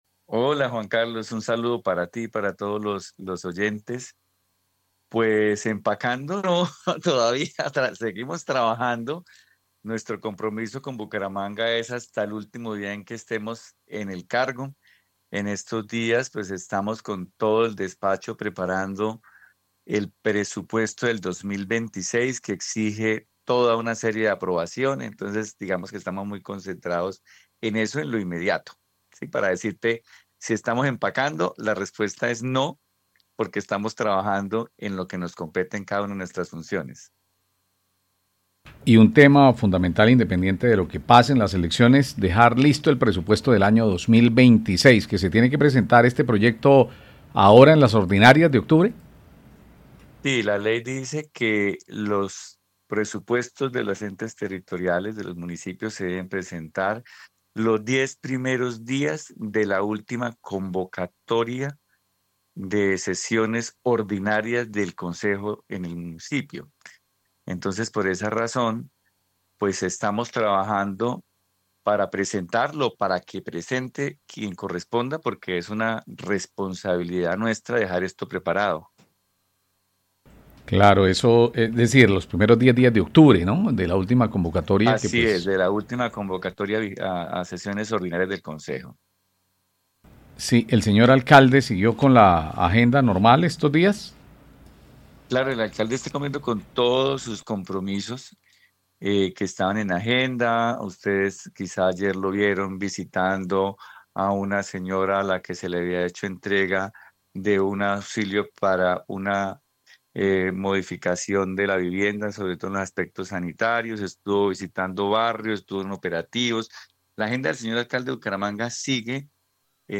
Richard Caicedo, jefe de gobernanza de Bucaramanga
El jefe de gobernanza de Bucaramanga, Richard Caicedo, confirmó que la Alcaldía de Bucaramanga se mantiene en plena actividad mientras se define la situación jurídica del alcalde, Jaime Andrés Beltrán. En diálogo con Caracol Radio el funcionario precisó que el equipo continúa preparando el proyecto de presupuesto para la vigencia 2026, el cual deberá ser radicado en el Concejo durante la primera semana de octubre.